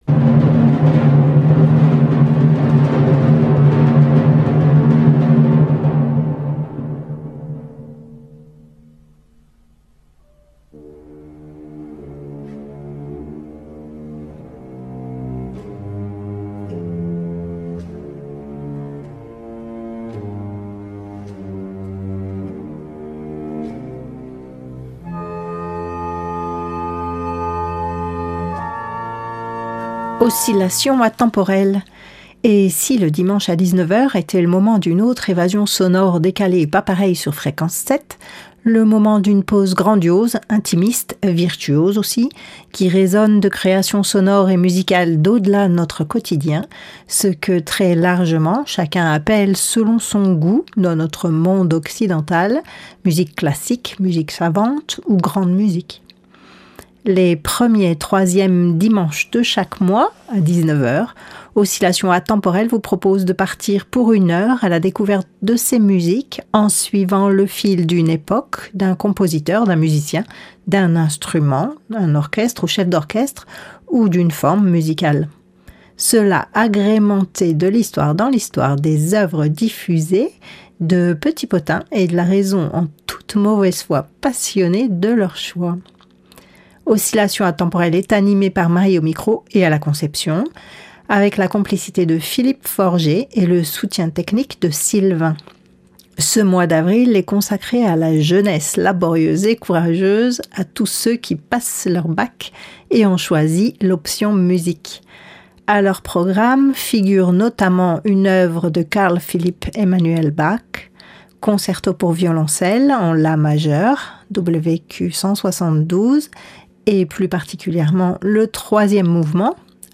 Hybrides et Classiques familiers